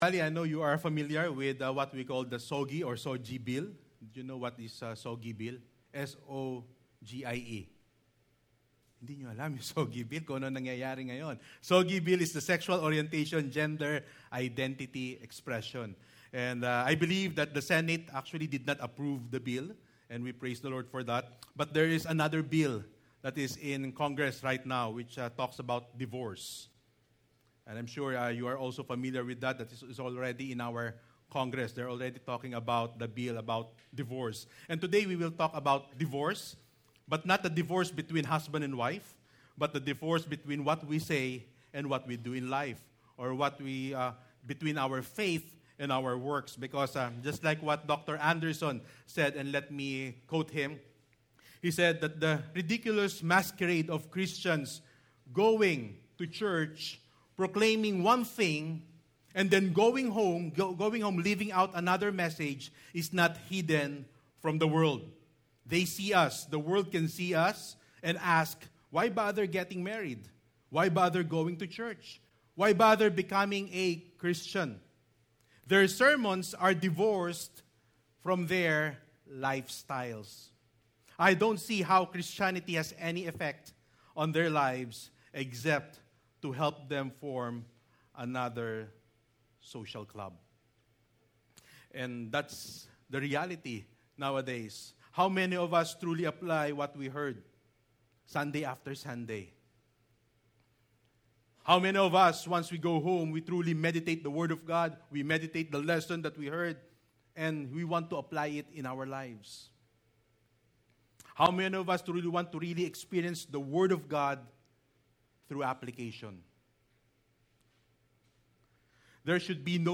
Bible Text: James 2:14-26 | Preacher